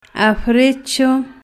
Phonological Representation af'ɾeʧo